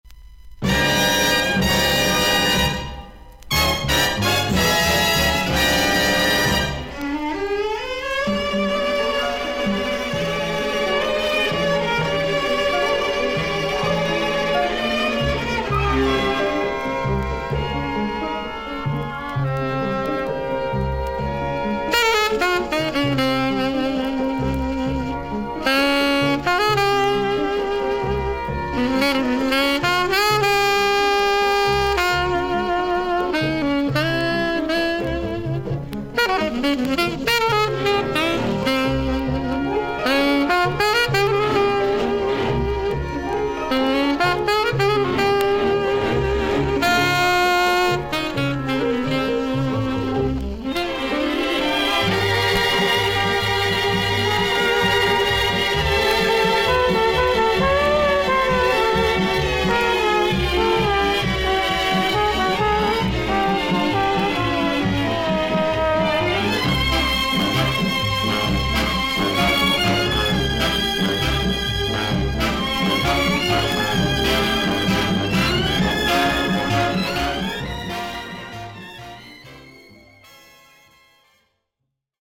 VG++〜VG+ 少々軽いパチノイズの箇所あり。クリアな音です。